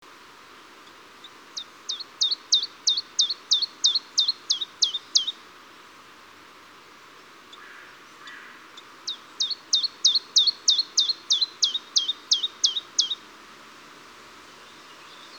Sekalaulava uunilintu / A song switching Phylloscopus warbler
The song of the bird was very variable, with both Chiffchaff Phylloscopus collybita and Willow Warbler Phylloscopus trochilus like elements.
Äänite 1 Tiltalttimaista laulua Recording 1 Chiffchaff-like song